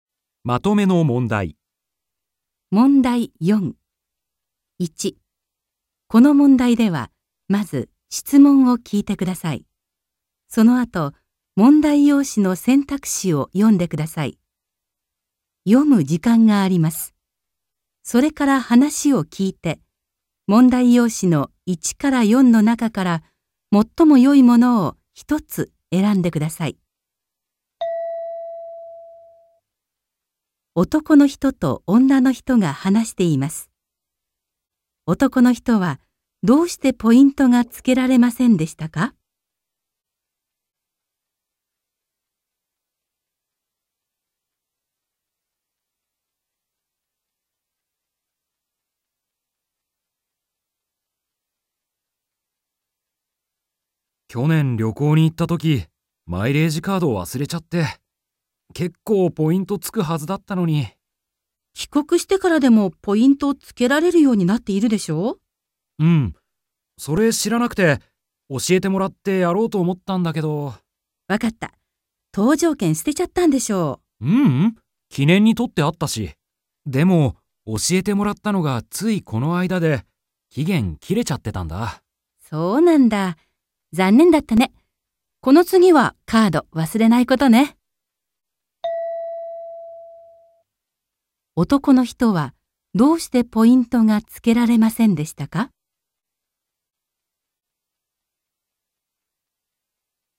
問題 4 ［聴解］